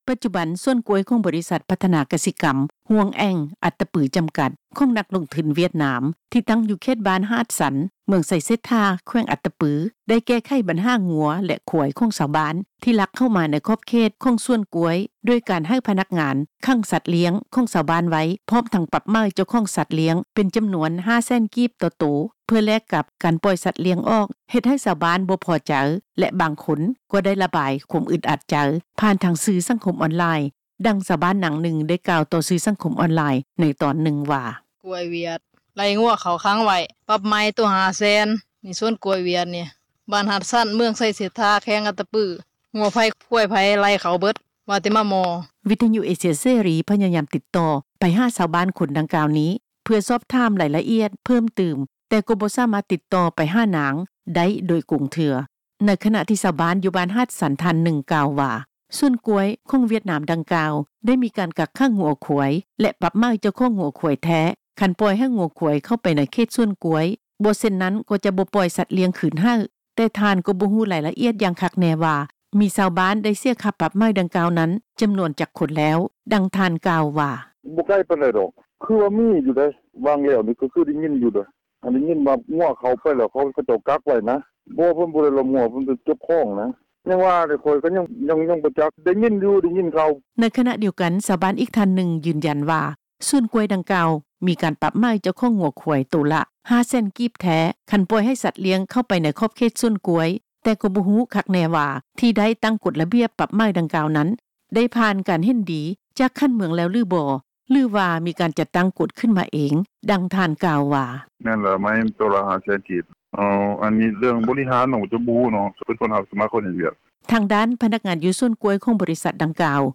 ດັ່ງ ຊາວບ້ານ ນາງນຶ່ງ ໄດ້ກ່າວຜ່ານສື່ສັງຄົມອອນລາຍ ໃນຕອນນຶ່ງວ່າ:
ດັ່ງ ນາງກ່າວວ່າ: